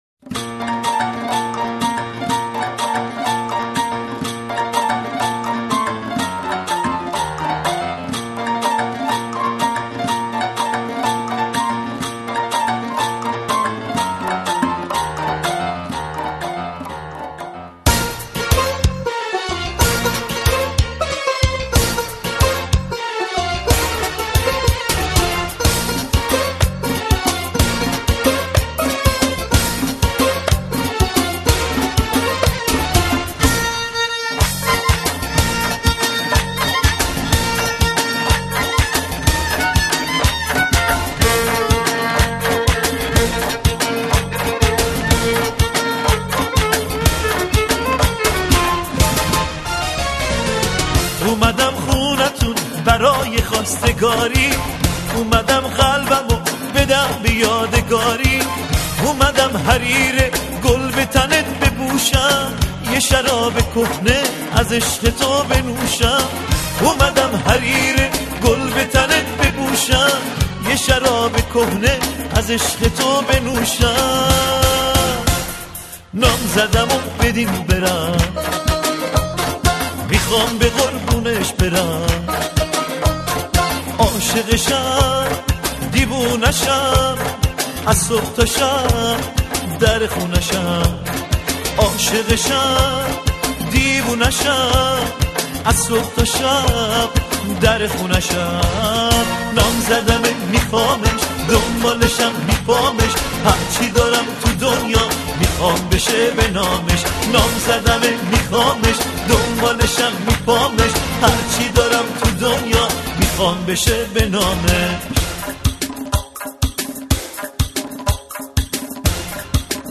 آهنگ قدیمی آهنگ شاد